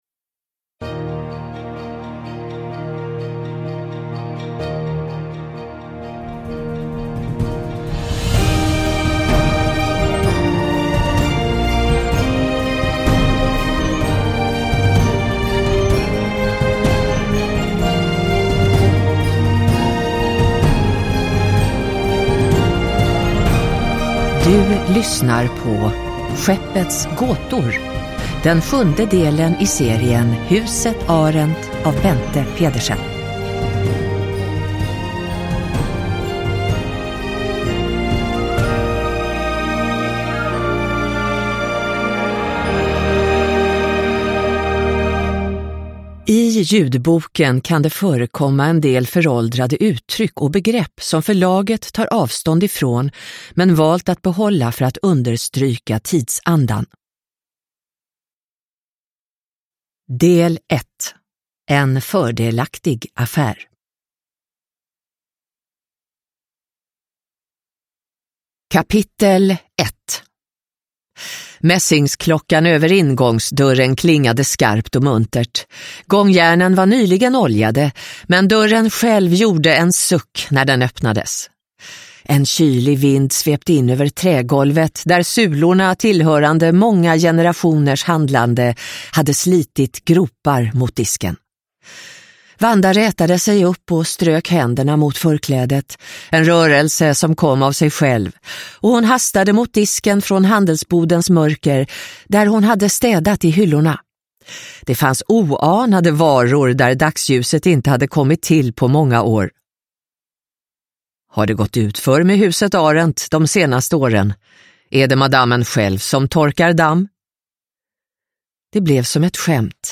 Skeppets gåtor – Ljudbok – Laddas ner